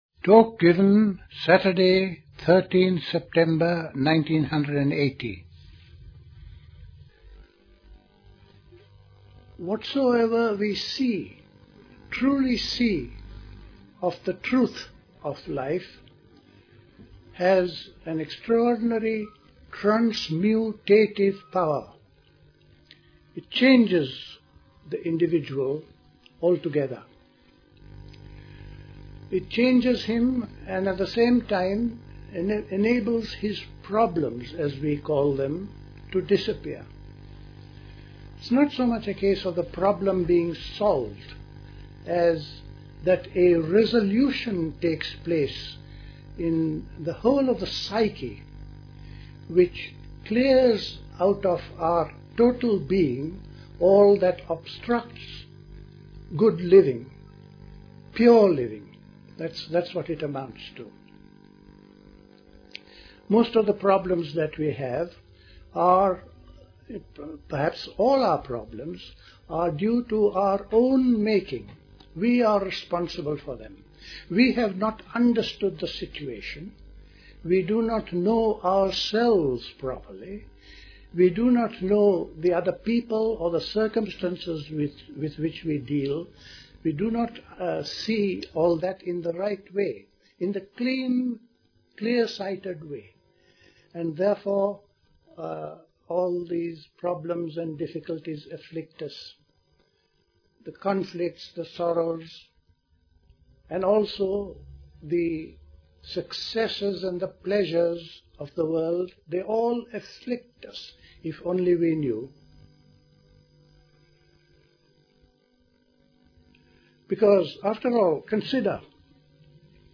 Play Talk